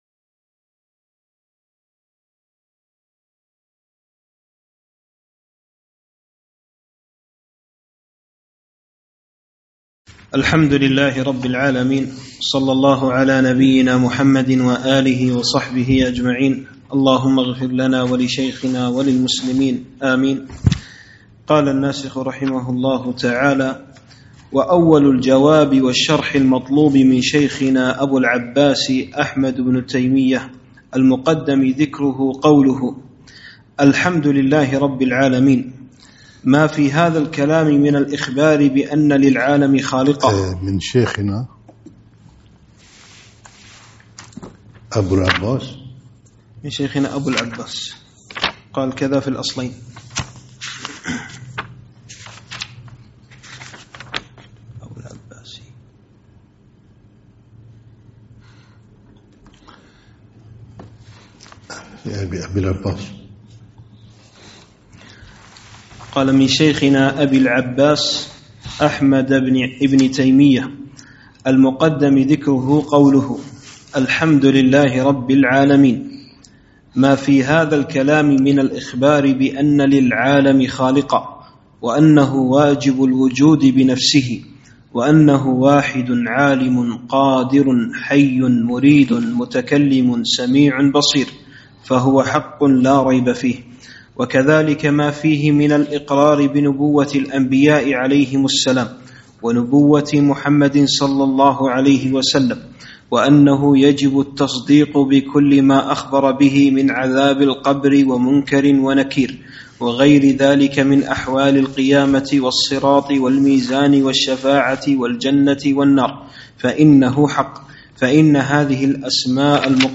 (2) شرح (شرح الأصفهانية) لابن تيمية رحمه الله - المجلس الثاني